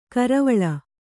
♪ karavaḷa